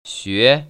[xué]
슈에